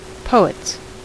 Ääntäminen
Ääntäminen US Haettu sana löytyi näillä lähdekielillä: englanti Käännöksiä ei löytynyt valitulle kohdekielelle. Poets on sanan poet monikko.